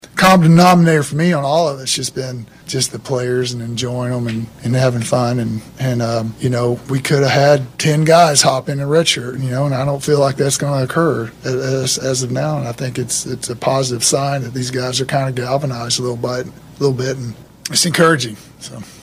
Oklahoma State interim head football coach Doug Meacham took the podium for his first weekly press conference on Monday in Stillwater.